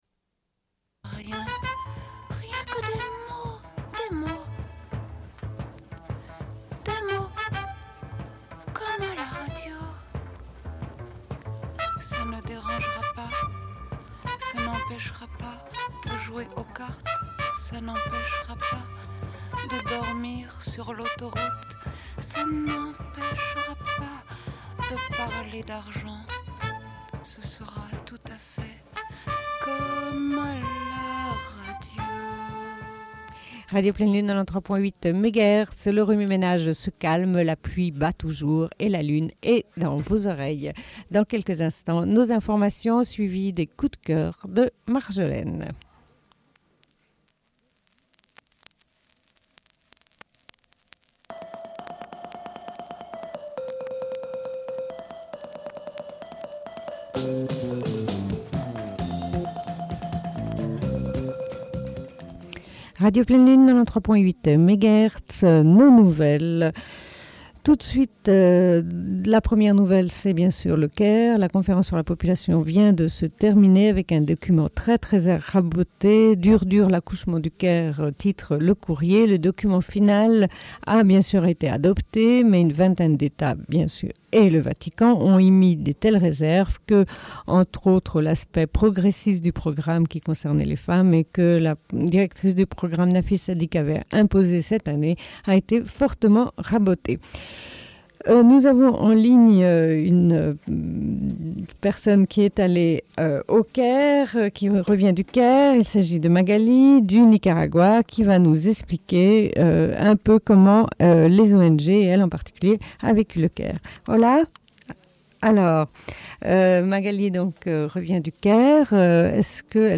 Bulletin d'information de Radio Pleine Lune du 14.09.1994 - Archives contestataires
Une cassette audio, face B29:40